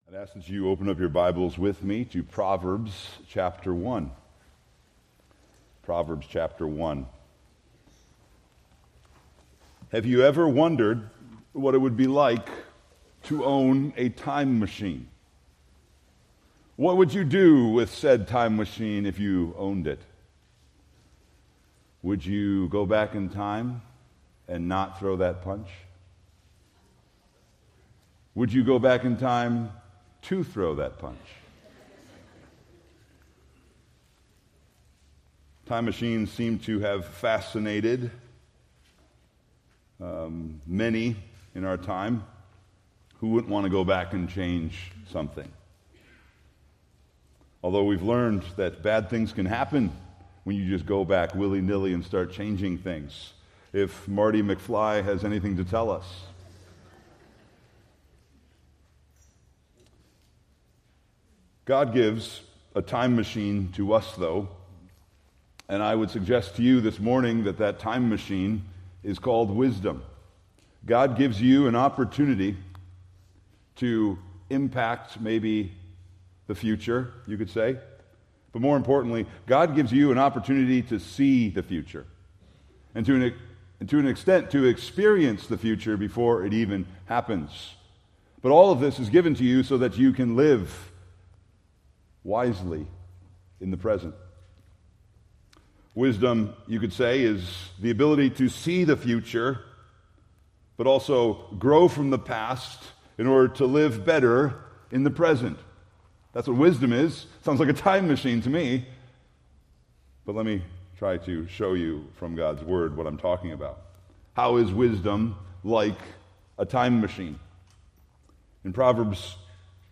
Preached July 20, 2025 from Proverbs 1:8-33